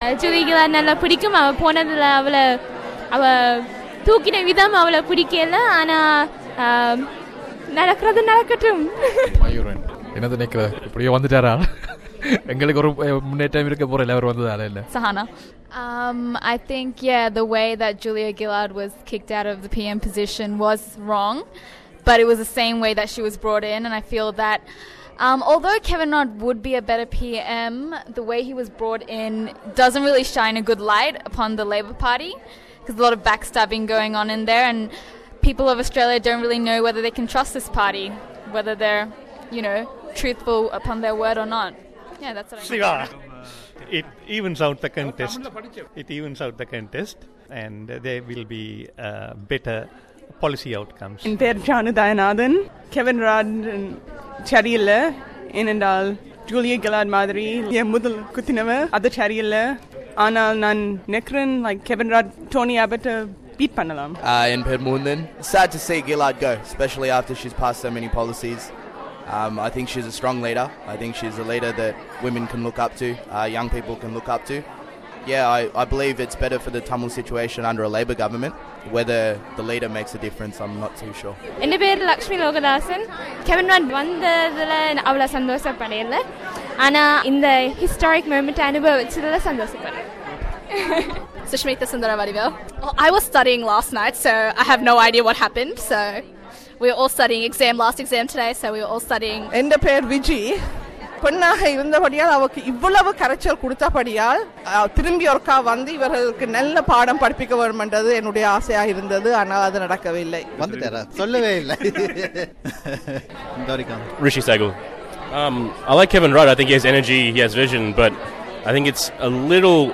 மக்கள் கருத்து